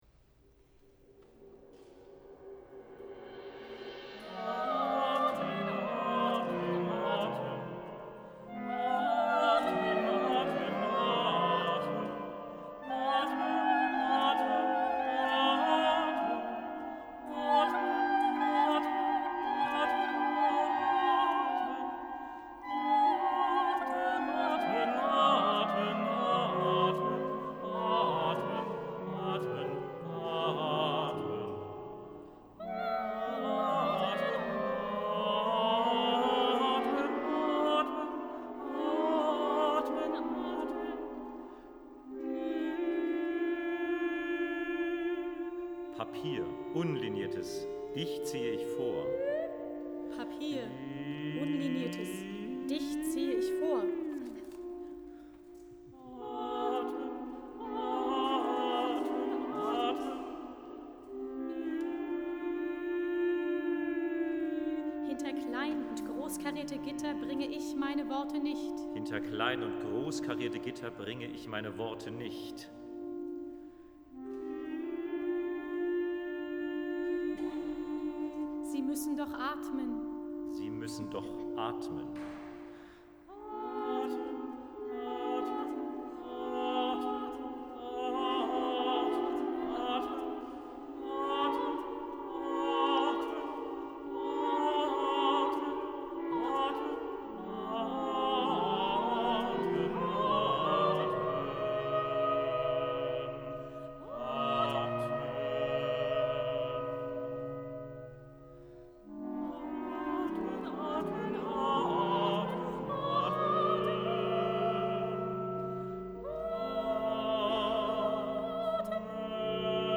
Adaption für Vokalquartett oder Kammerchor